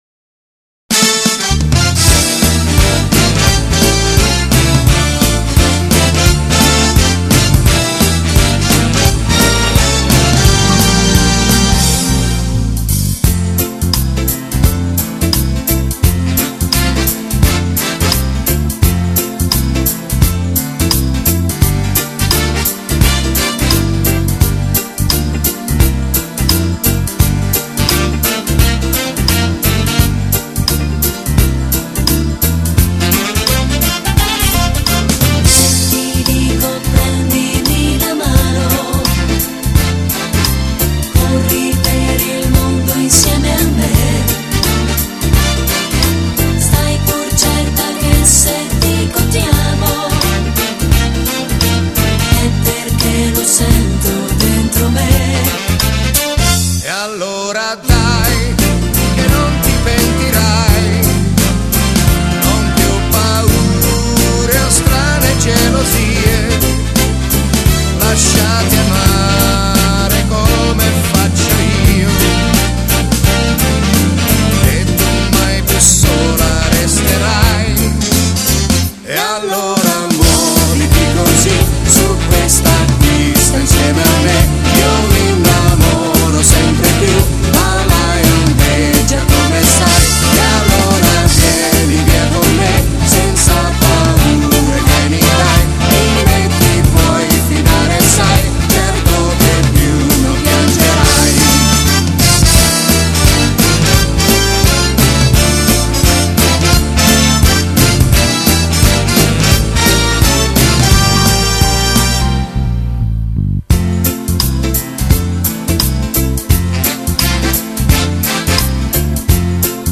Genere: Fox trot
Scarica la Base Mp3 (3,75 MB)